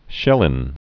(shĭlĭn)